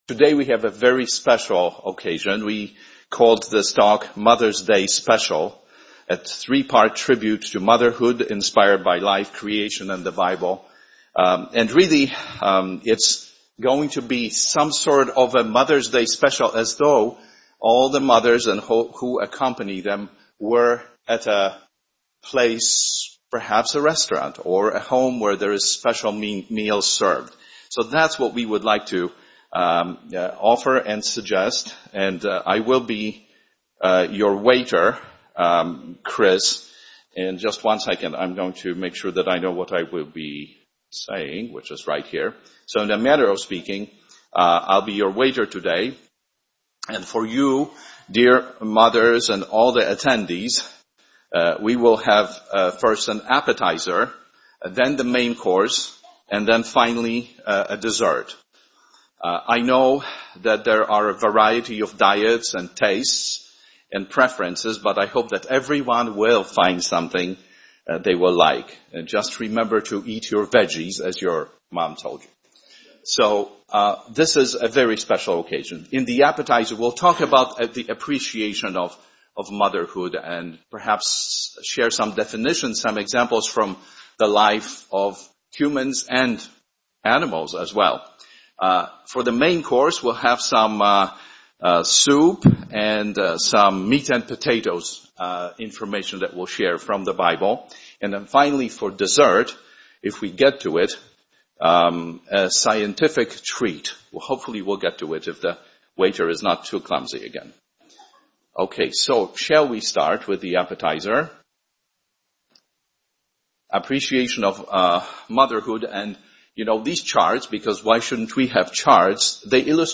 Series: Chicago Sermons